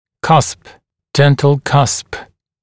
[kʌsp] [‘dentl kʌsp][касп] [‘дэнтл касп]бугор зуба